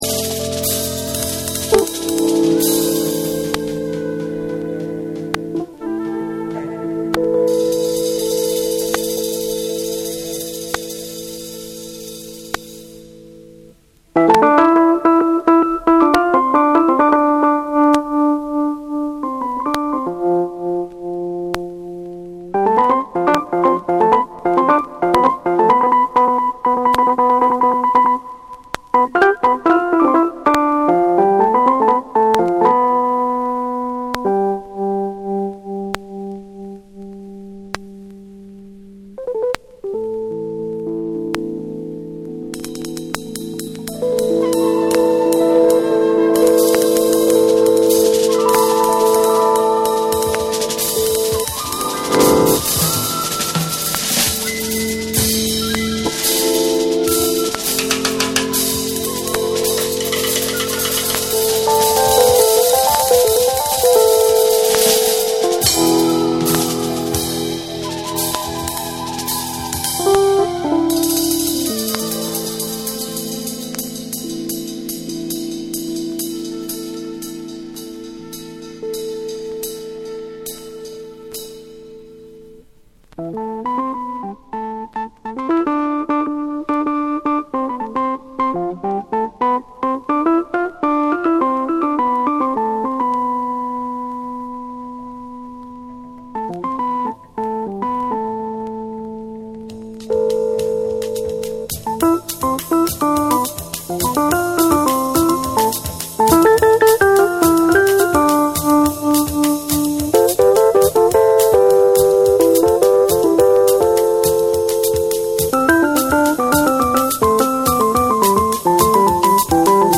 プチノイズ入る箇所あり
SOUL & FUNK & JAZZ & etc